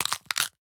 Minecraft Version Minecraft Version latest Latest Release | Latest Snapshot latest / assets / minecraft / sounds / mob / turtle / egg / egg_crack3.ogg Compare With Compare With Latest Release | Latest Snapshot
egg_crack3.ogg